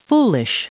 Prononciation du mot foolish en anglais (fichier audio)
Prononciation du mot : foolish